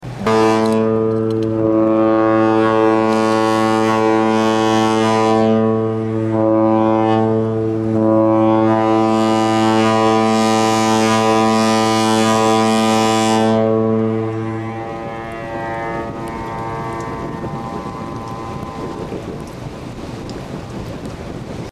Einfahrt in Bergen :
Horn_Bergen.mp3